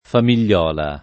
famigliola [ fam & l’l’ola o famil’l’ 0 la ]